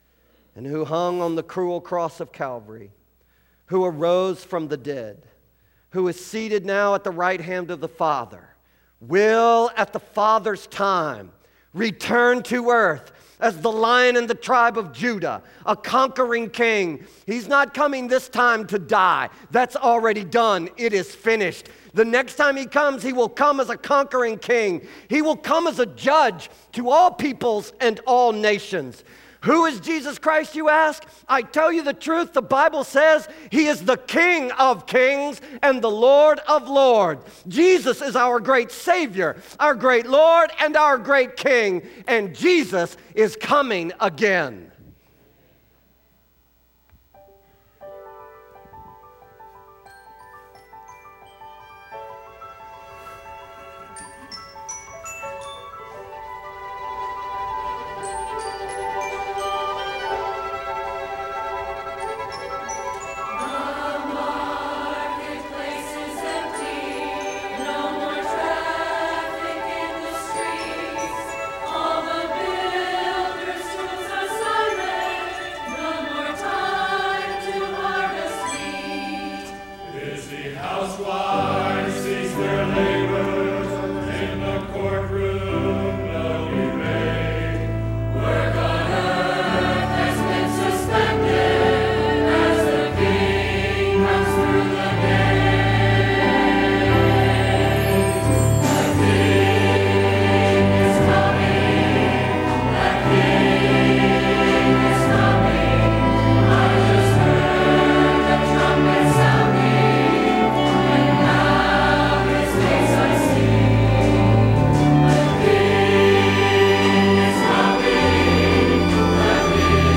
Both our Choir with the Orchestra and the Hyssongs did great and I couldn’t decide so here are both of them.
the-king-is-coming-faith-baptist-choir-and-orchestra.mp3